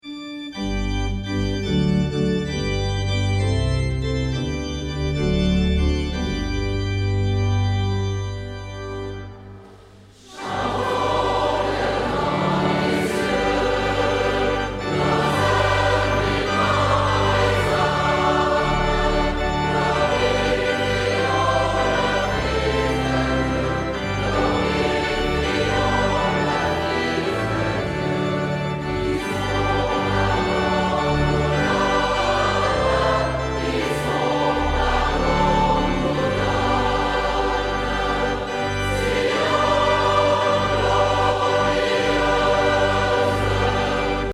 Chor, Orgel